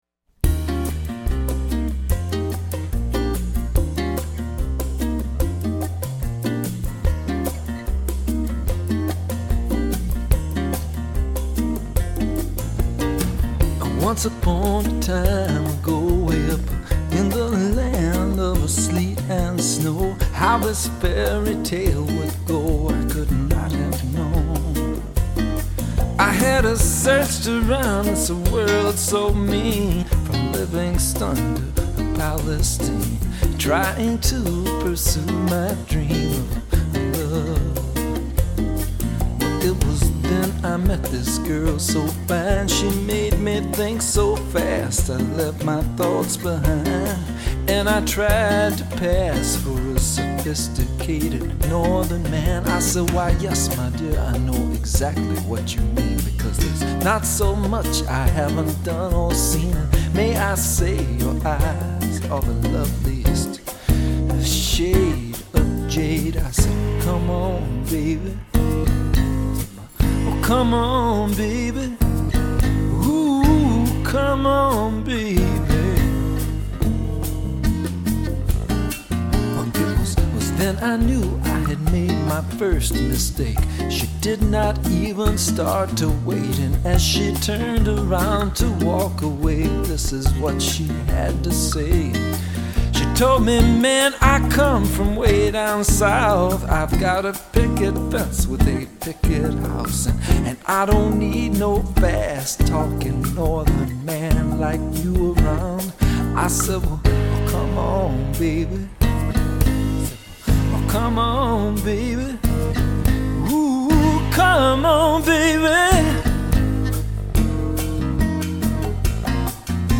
a swinging, almost bossa nova, feel